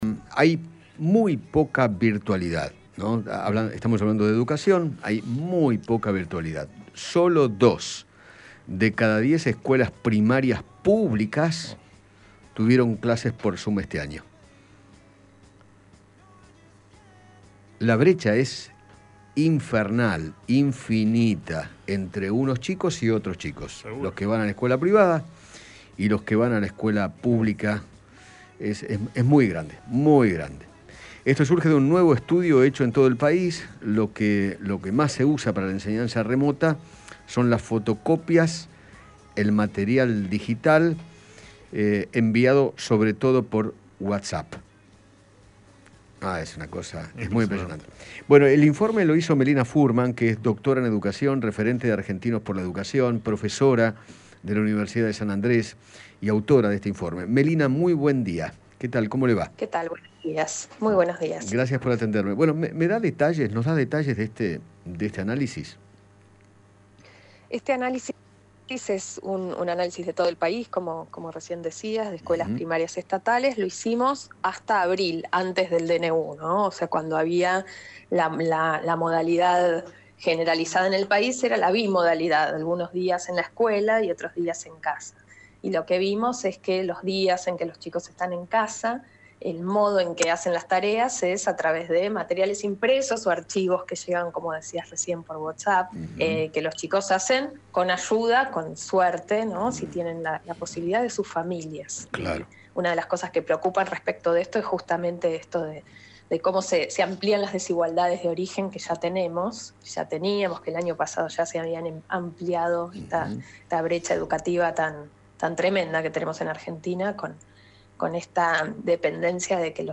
conversó con Eduardo Feinmann acerca de la cantidad de tiempo que le dedican los jóvenes cuando tienen clases desde sus casas y expresó su preocupación frente a las  desigualdades en materia educativa.